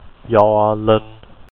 Gio_Linh.ogg.mp3